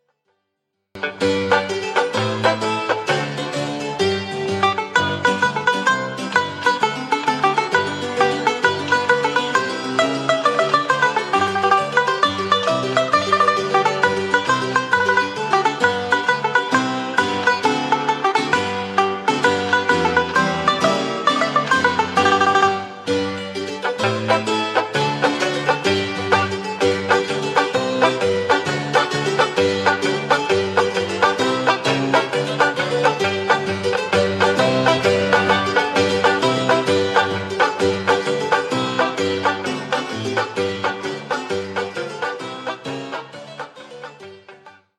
12-beat intro.
This song is in 3/4 waltz time.